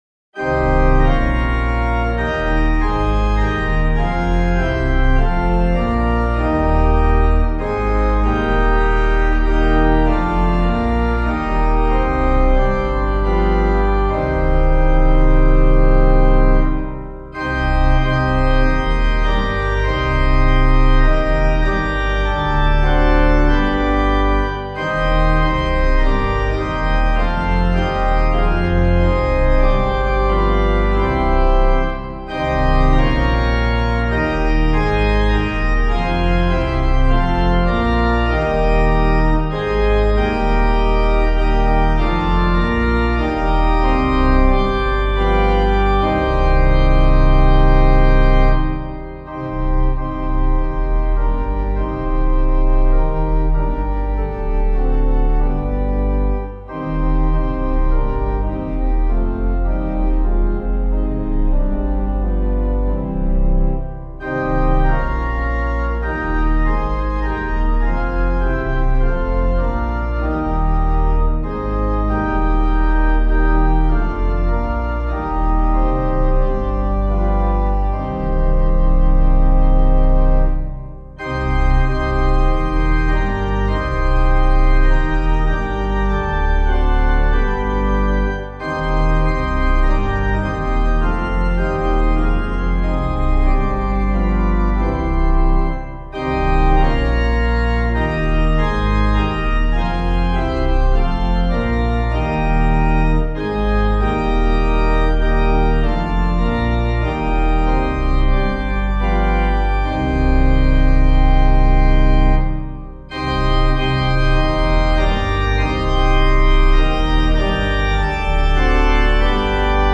Meter:    88.88
Composer:    adapted from The Agincourt Song, 15th cent. English melody;